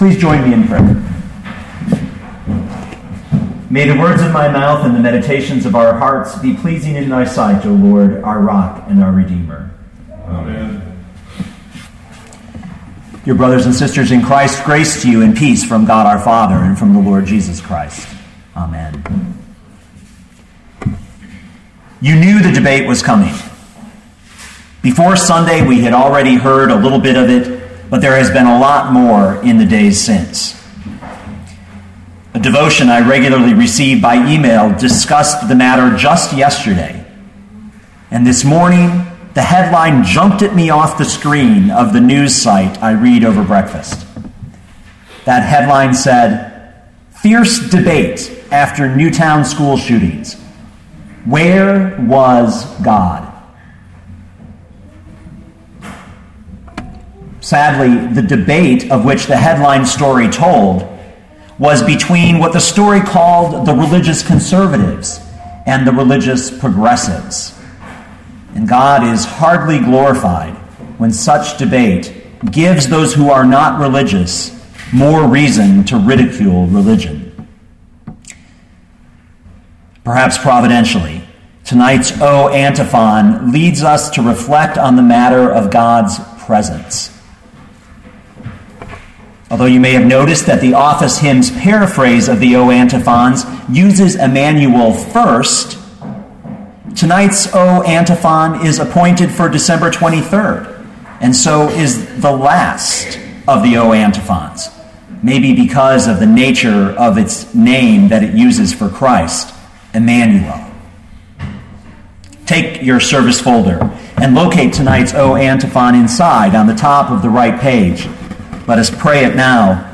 Matthew 1:18-25 Listen to the sermon with the player below, or, download the audio.